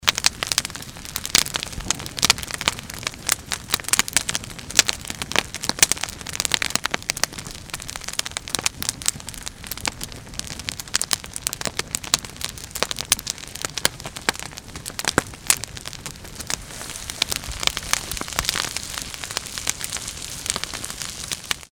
mf_SE-6272-campfires_1.mp3